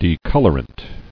[de·col·or·ant]